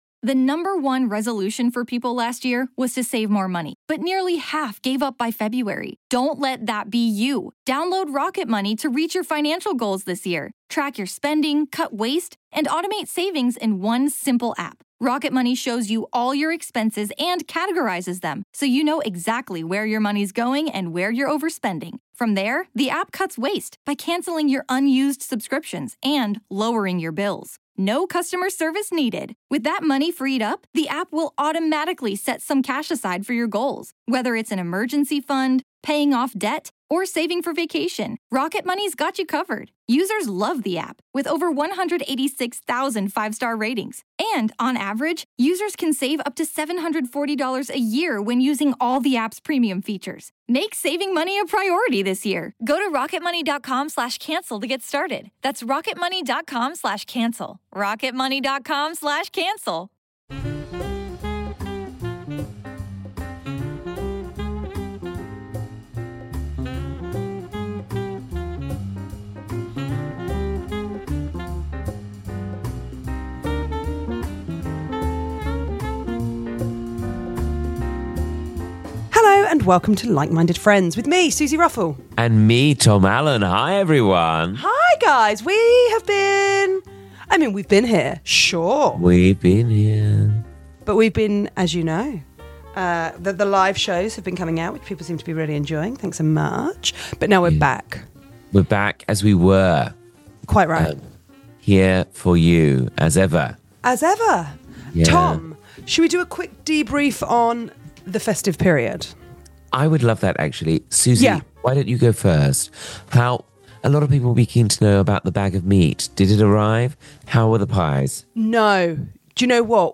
Comedians and dearest pals Tom Allen and Suzi Ruffell chat friendship, love, life, and culture... sometimes...